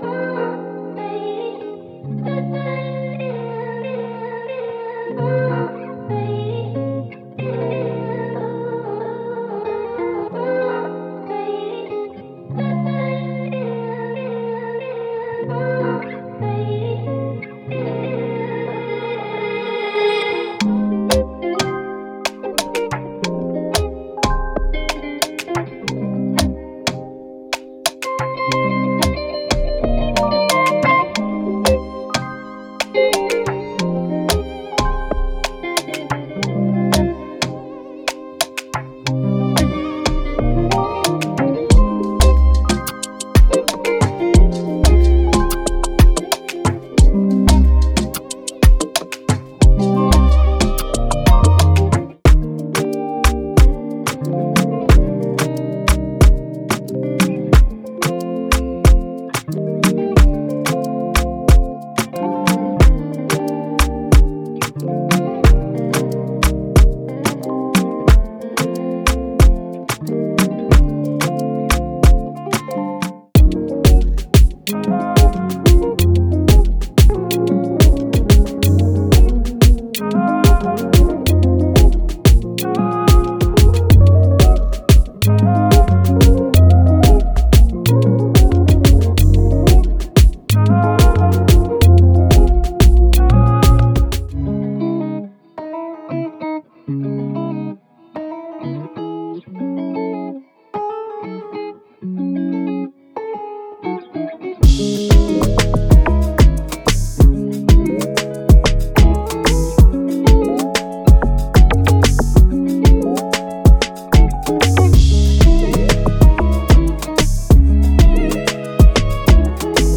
From deep, emotive chords to captivating rhythms, each sound is designed to inspire a wide range of moods, allowing you to fully unlock your creative potential. With its blend of rich harmonies and unforgettable, soul-stirring melodies, this pack provides the perfect foundation for crafting standout Afro RnB and Soul tracks. This pack is a treasure, a substantial gem trove of authentic and vibrant Guitar Melodies.